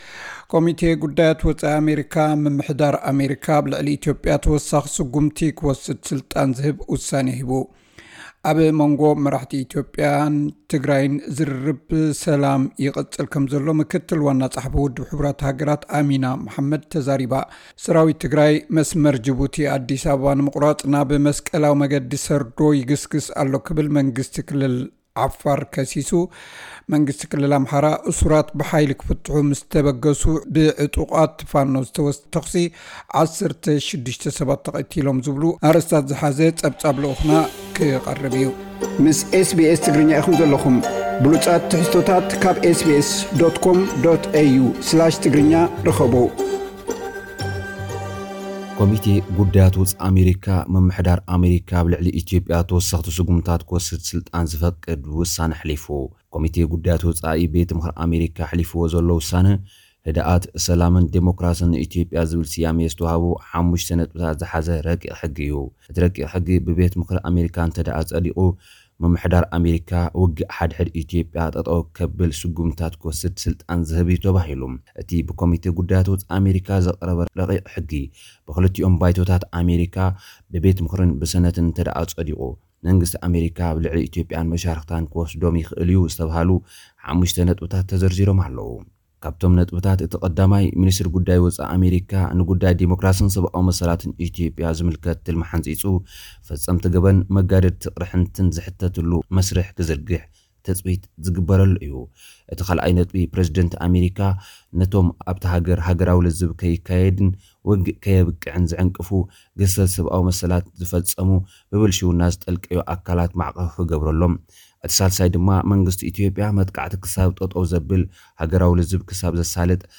ዝብሉ ኣርእስታት ዝሓዘ ጸብጻብ ልኡኽና ክቐርብ እዩ።